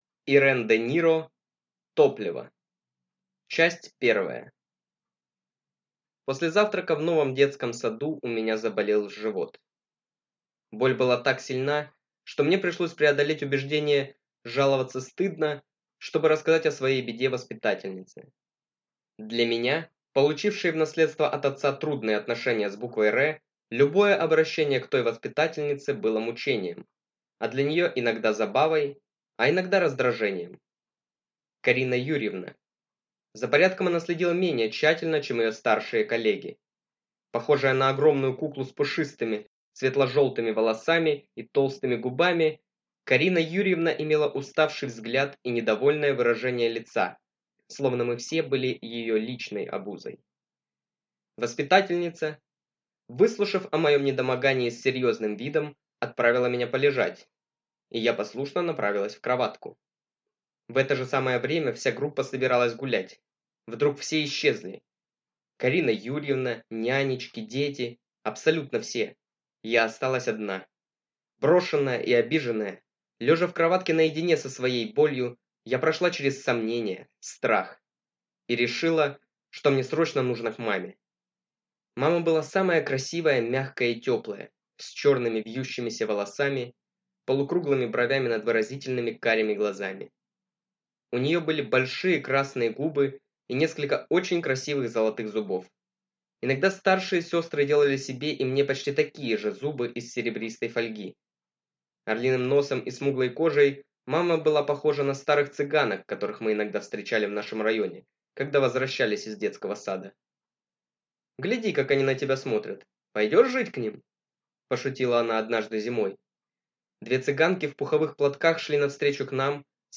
Аудиокнига Топливо.